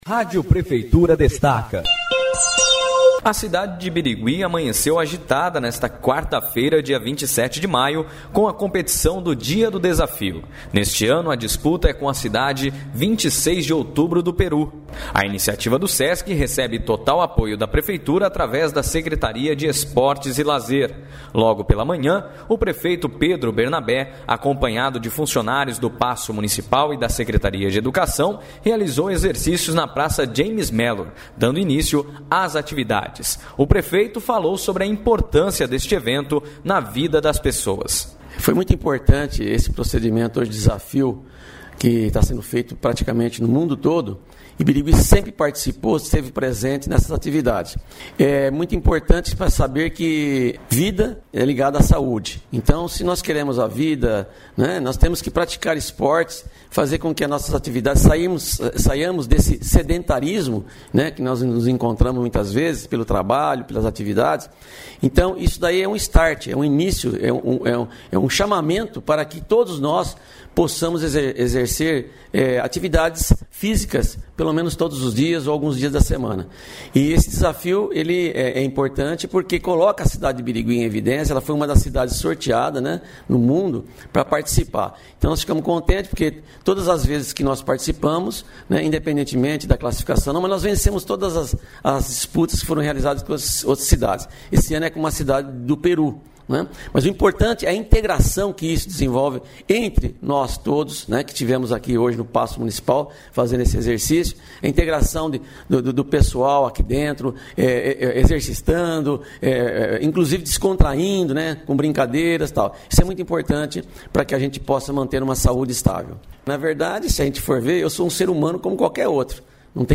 A Rádio Prefeitura acompanhou as atividades e falou com o prefeito Pedro Bernabé, que descatou a importância da prática esportiva para saúde.
Sonora: